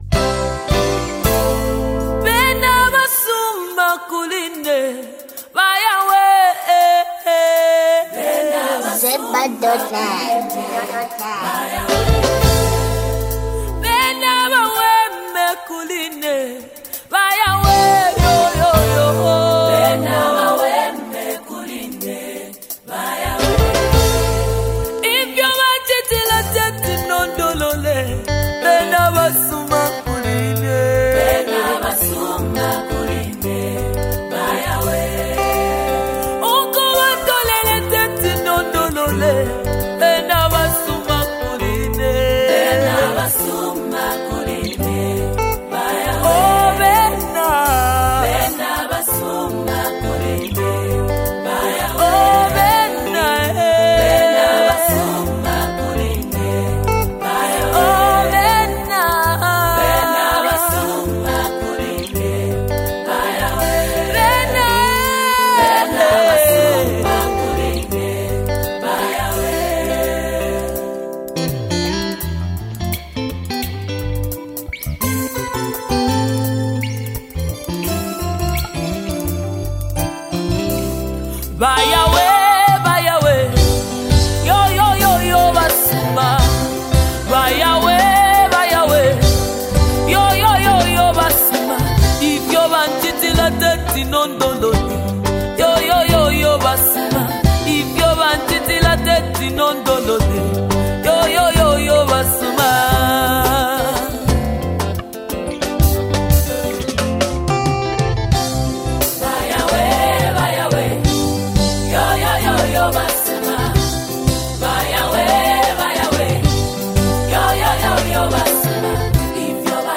Its infectious sound will have you dancing in no time.